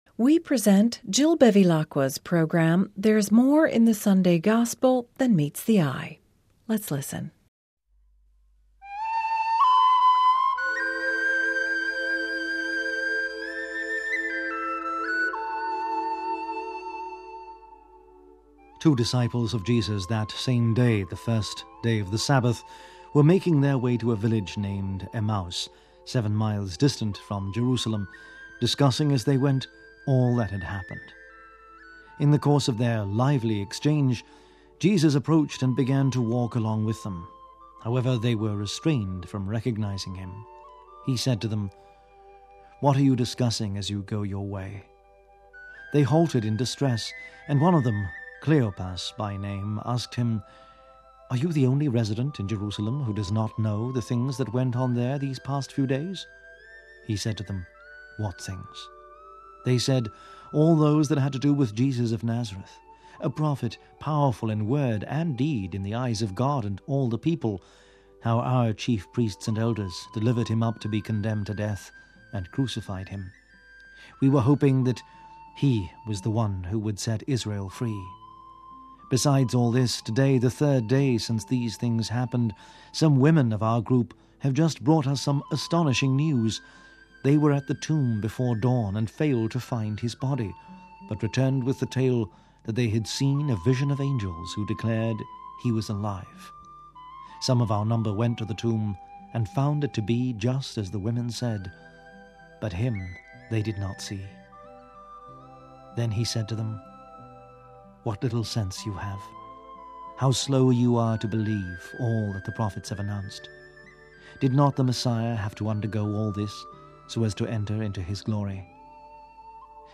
readings and reflections for the Third Sunday of Easter